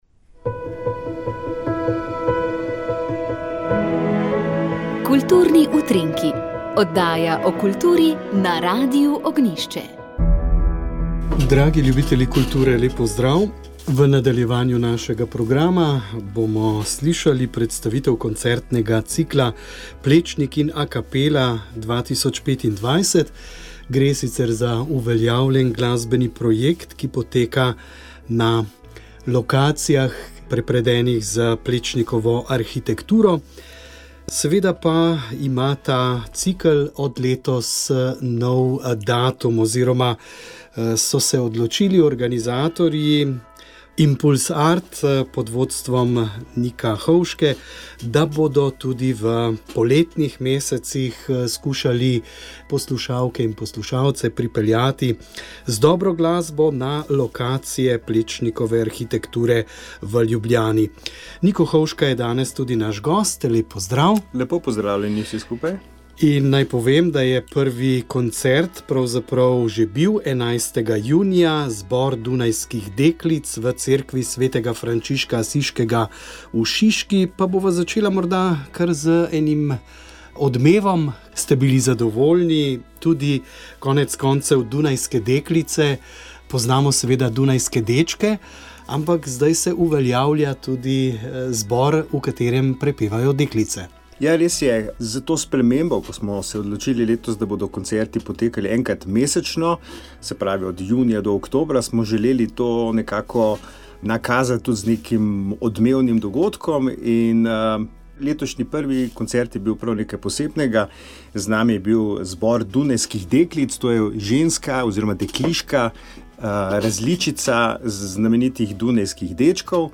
Naši gostje na praznik Marjinega vnebovzetja so bili trije duhovniki